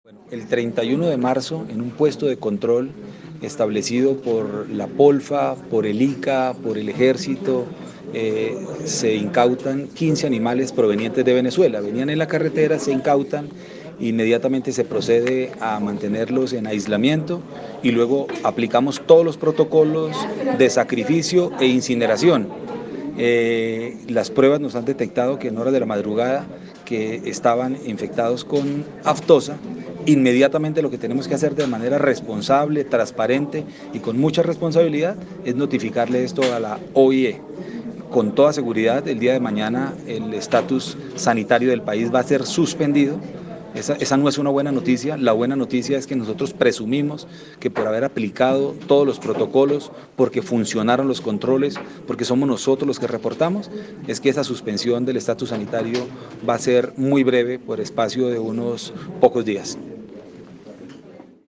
Rueda de prensa
• Escuche aquí la declaración del ministro de Agricultura: